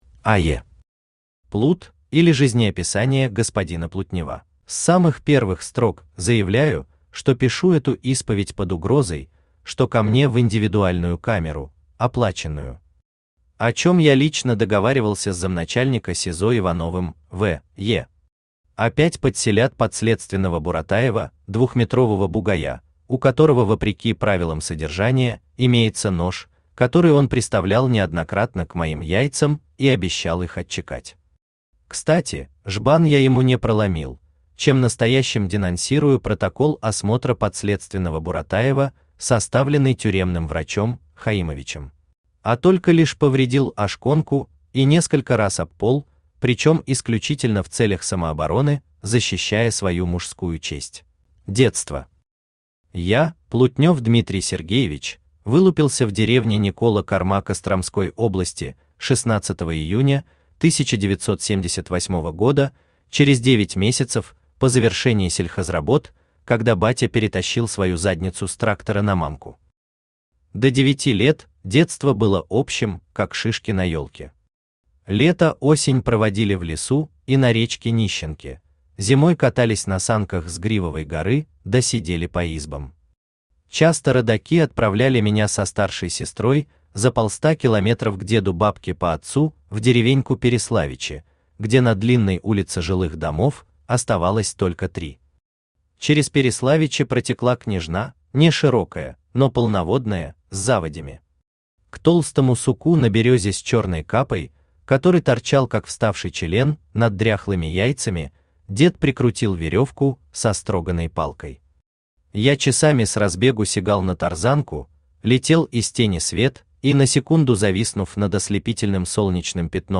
Aудиокнига Плут, или Жизнеописание господина Плутнева Автор АЕ Читает аудиокнигу Авточтец ЛитРес.